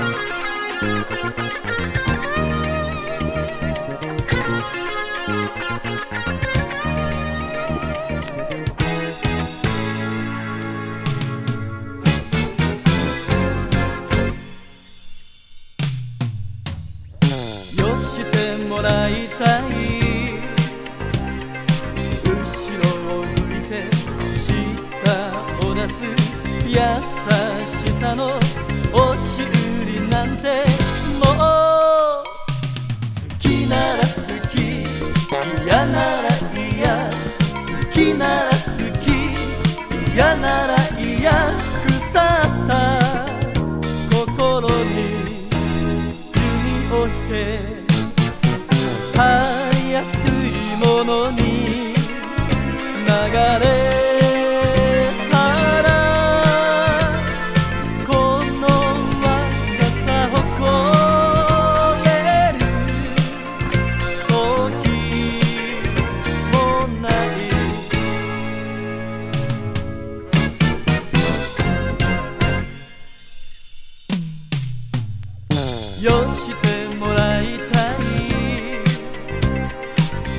ドラムブレイク有の疾走ホーン・チェイサー・ファンク